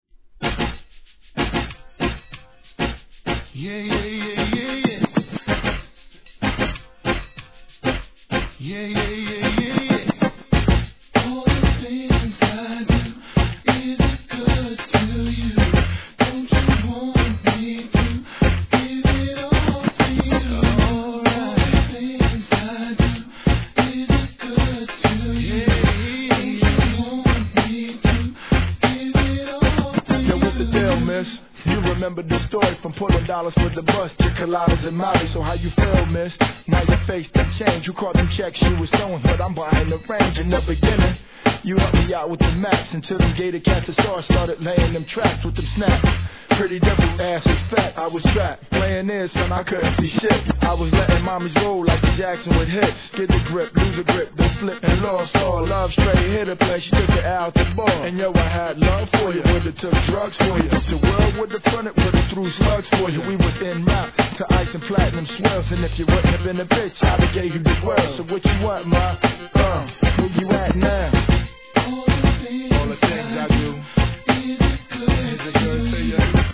HIP HOP/R&B
コーラスで聴かせるフックがNICEな怒インディー物!